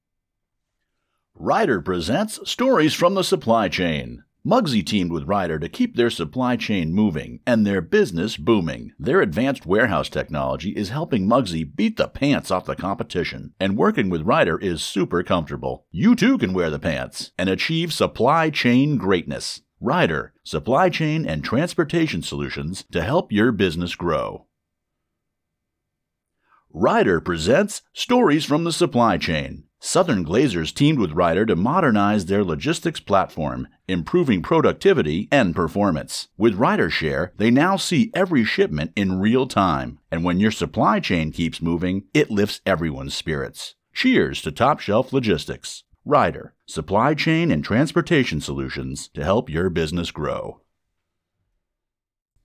English - Midwestern U.S. English
Middle Aged